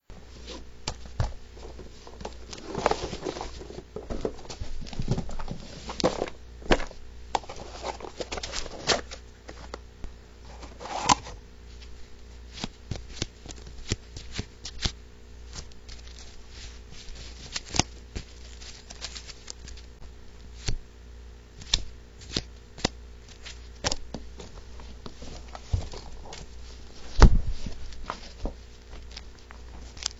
Field Recording #8
My Room, Centereach I start off the clip by grabbing and moving a few of my Yu-Gi-Oh card boxes toward me. I open up a Deck box and start flipping through some of the cards.